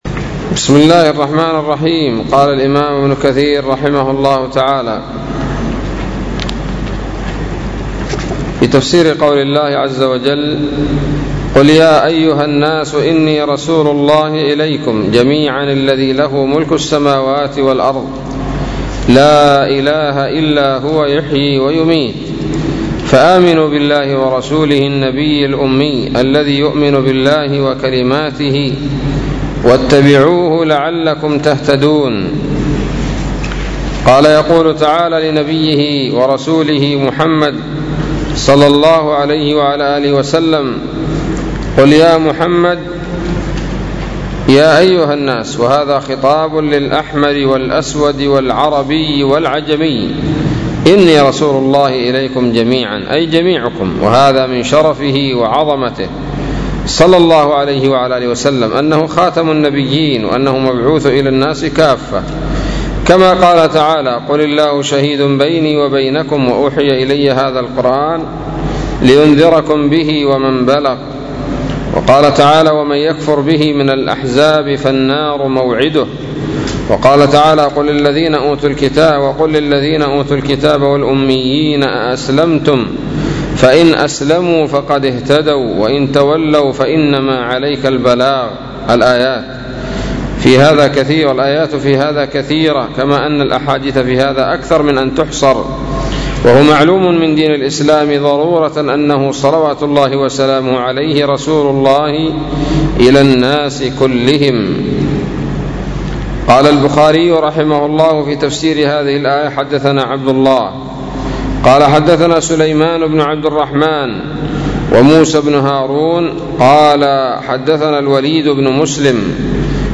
الدرس الثالث والخمسون من سورة الأعراف من تفسير ابن كثير رحمه الله تعالى